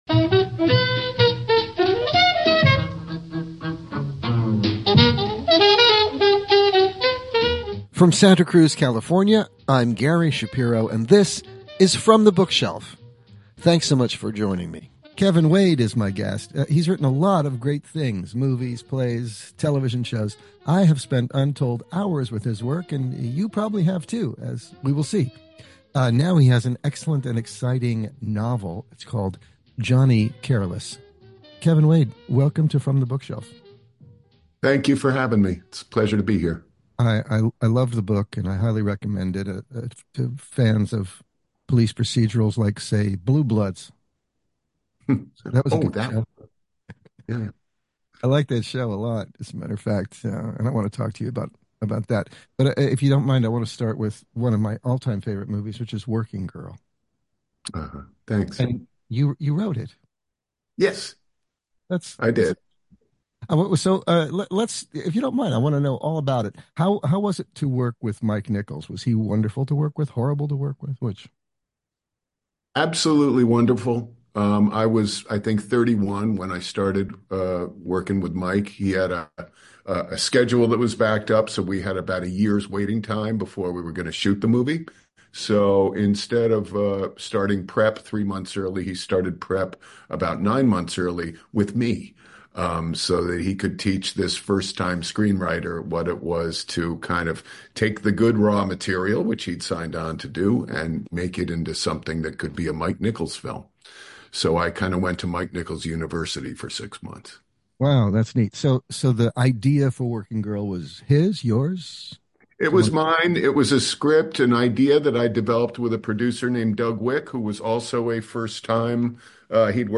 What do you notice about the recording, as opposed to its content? From the Bookshelf is heard on radio station KSQD in Santa Cruz California.